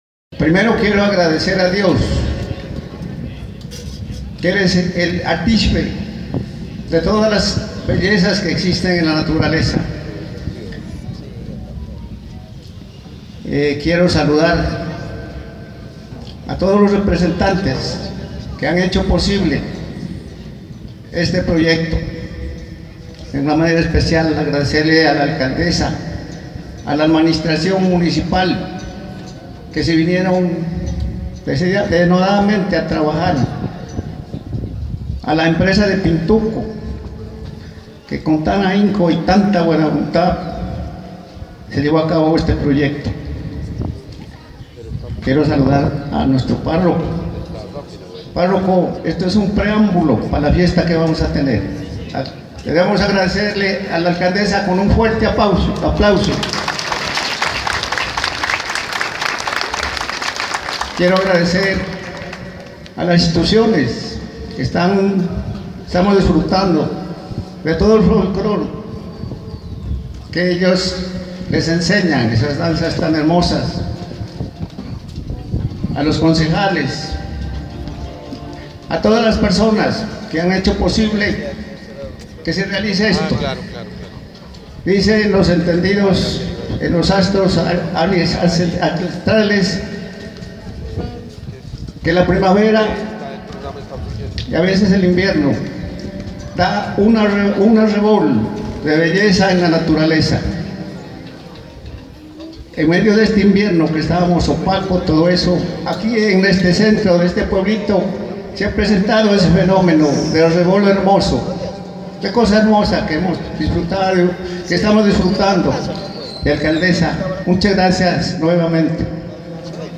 En el parque de la vereda Ingenio Centro este martes en la mañana se realizó el lanzamiento del programa “Sandoná pueblo que enamora, con arte pintura y color”.